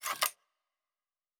pgs/Assets/Audio/Sci-Fi Sounds/Weapons/Weapon 14 Foley 2 (Flamethrower).wav at master
Weapon 14 Foley 2 (Flamethrower).wav